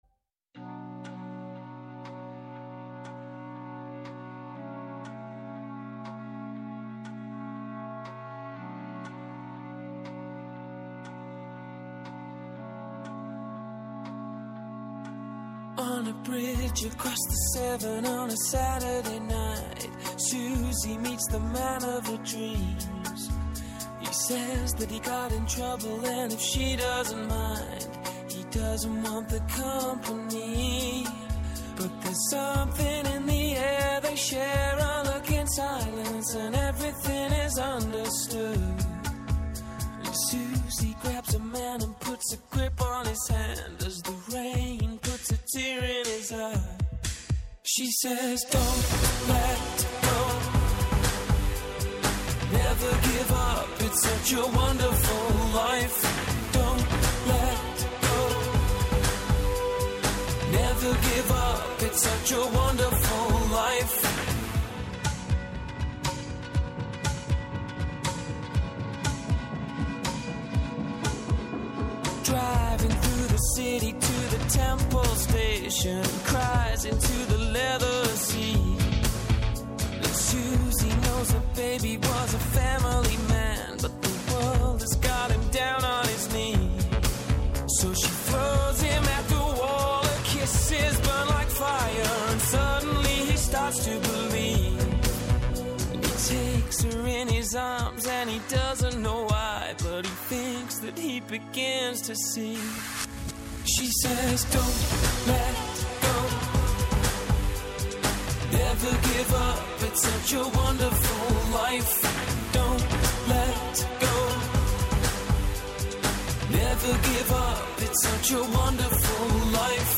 Καλεσμένος στο στούντιο